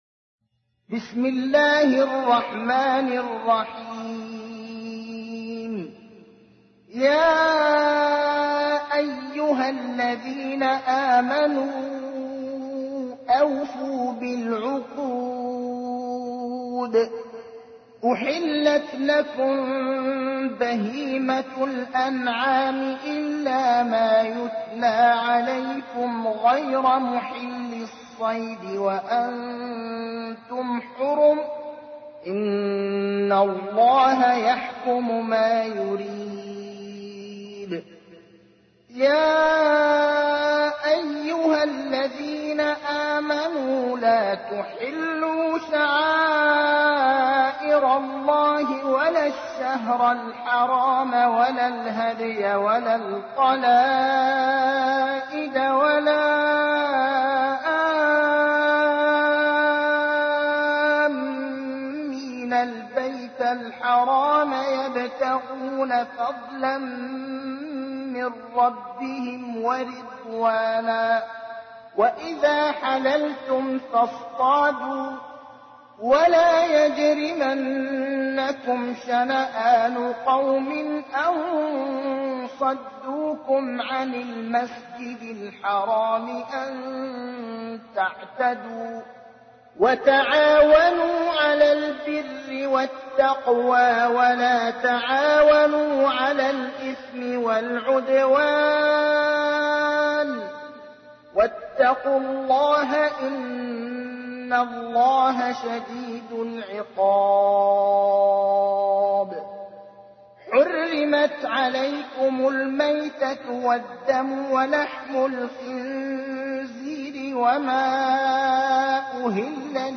تحميل : 5. سورة المائدة / القارئ ابراهيم الأخضر / القرآن الكريم / موقع يا حسين